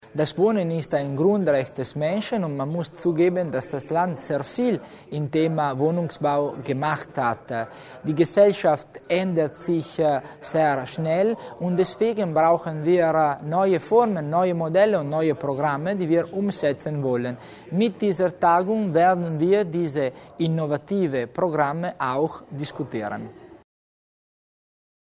Landesrat Tommasini zur Idee Social Housing